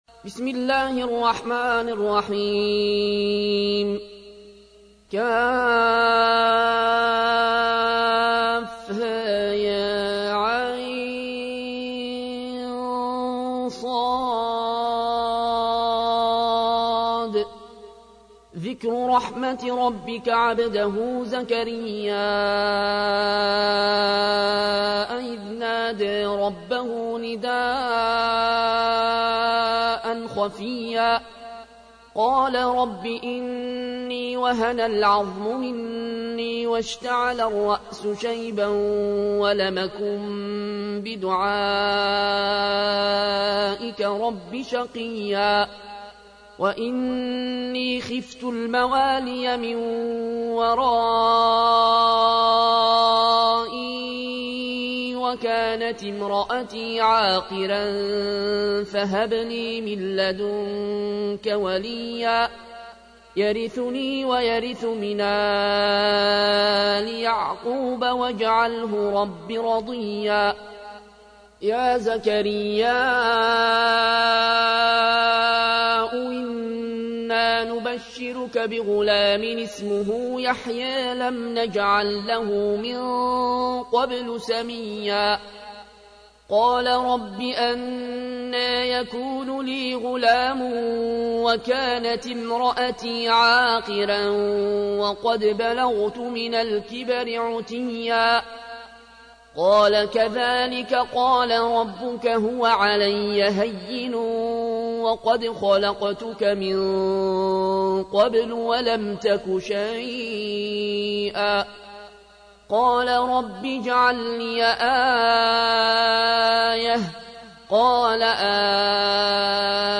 تحميل : 19. سورة مريم / القارئ العيون الكوشي / القرآن الكريم / موقع يا حسين